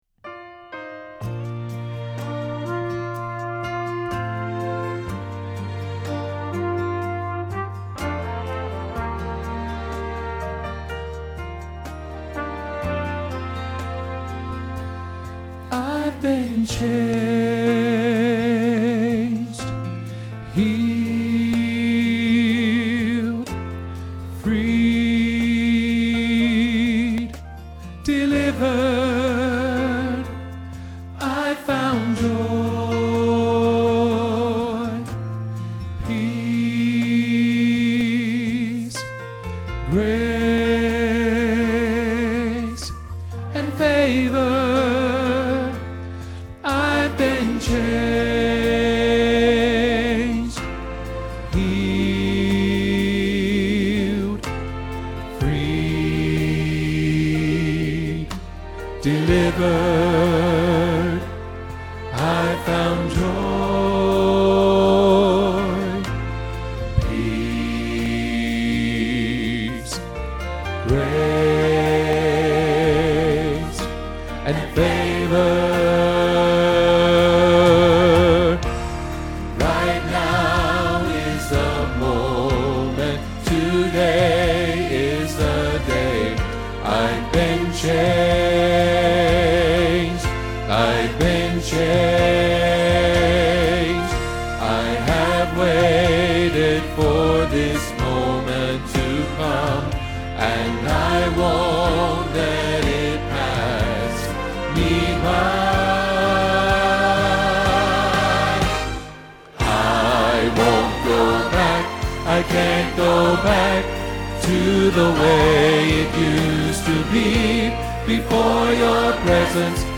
I Won’t Go Back – Bass – Hilltop Choir
I Won’t Go Back – Bass Hilltop Choir